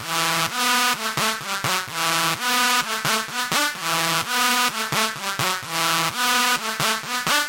EDM 旋律
它是用Sylenth1制作的。
Tag: 128 bpm Dance Loops Synth Loops 1.26 MB wav Key : A